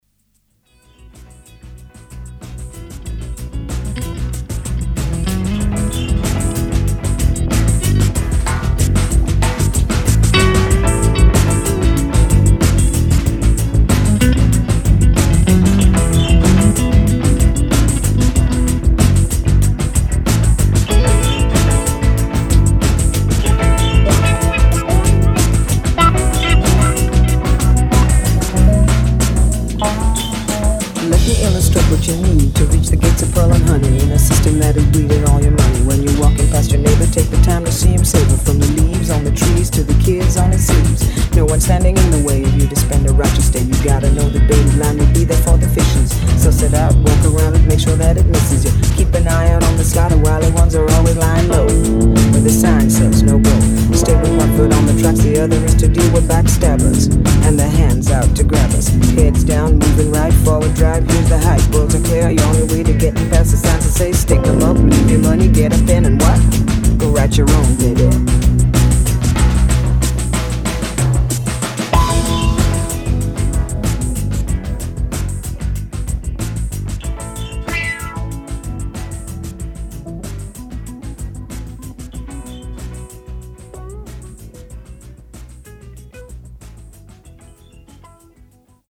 vox
een funky jazzsfeer
midtempo funky songs